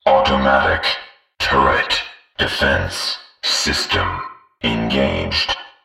voxTurretOn.ogg